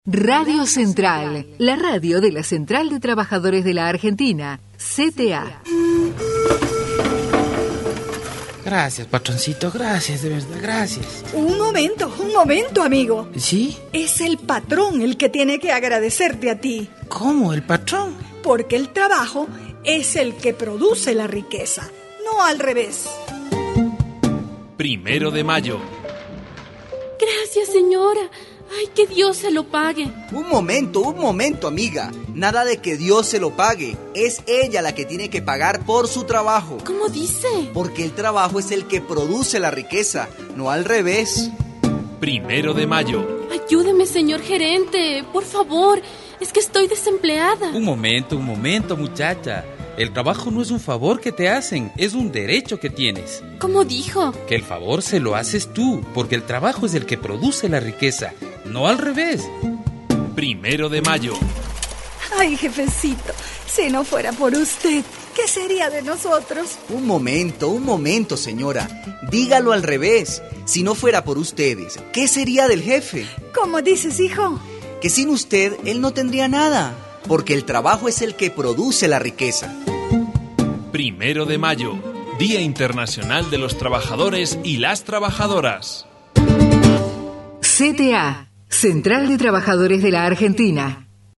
RADIO CENTRAL, presenta: "QUIEN CREA LA RIQUEZA" - campaña radial en el Día Internacional de los Trabajadores y las trabajadoras